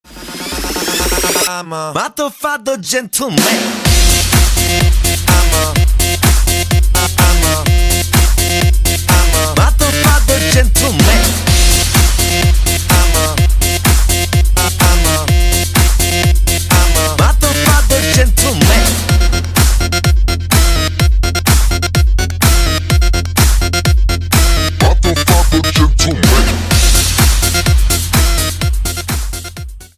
Ringtones Category: Korean Music